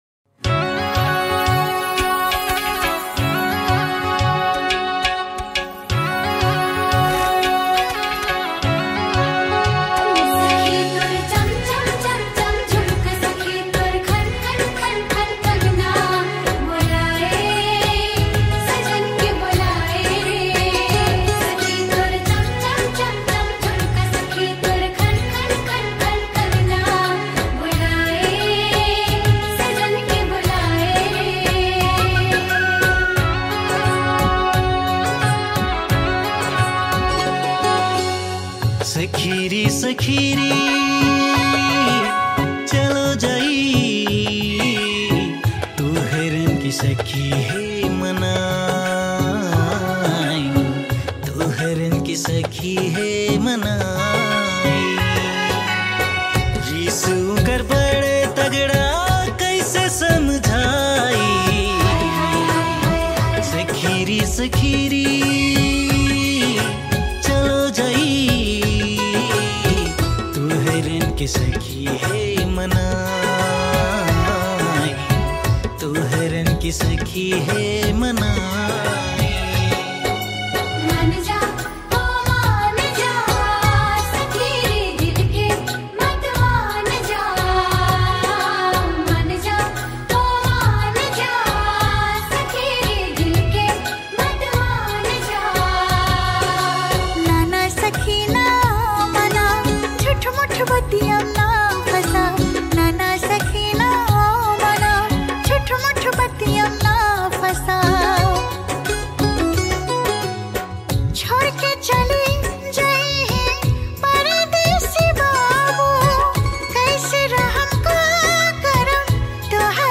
New Tharu Mp3 Song